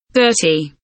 dirty kelimesinin anlamı, resimli anlatımı ve sesli okunuşu